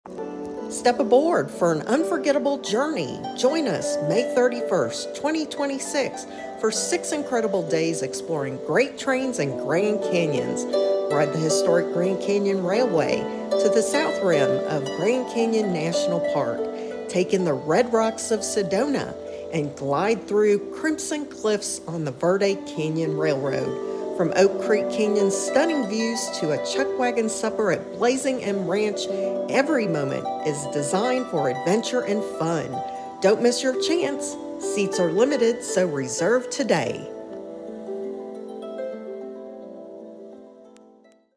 4.-May-31-train_and_harp.mp3